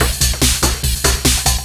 DS 144-BPM A2.wav